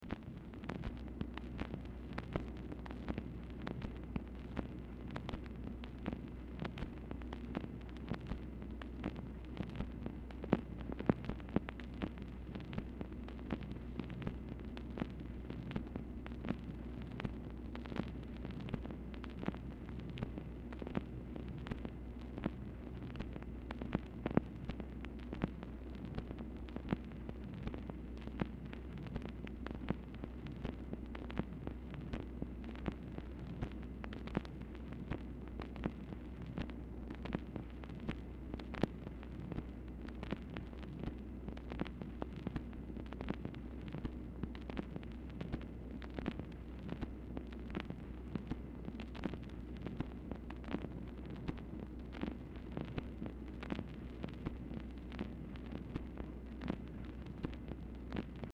Telephone conversation # 13827, sound recording, MACHINE NOISE, 12/30/1968, time unknown | Discover LBJ
Format Dictation belt
Specific Item Type Telephone conversation